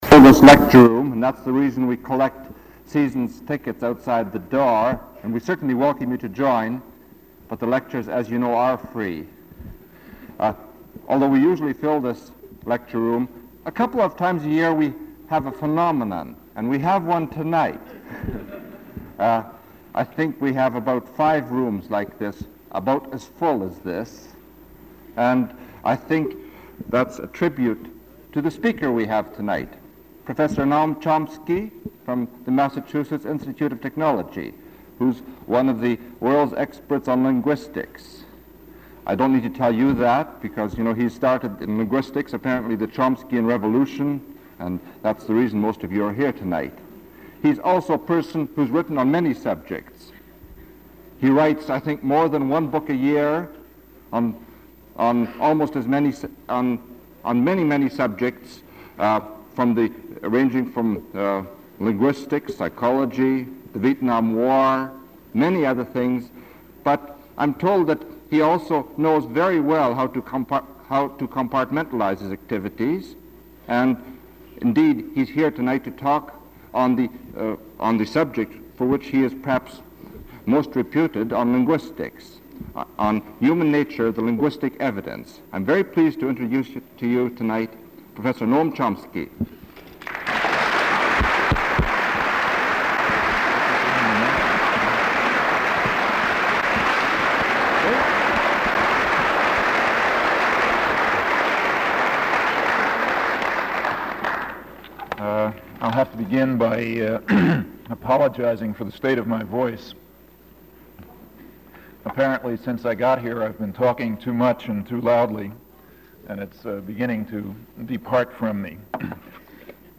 Item consists of a digitized copy of an audio recording of a Cecil and Ida Green lecture delivered at the Vancouver Institute by Noam Chomsky on January 24, 1976.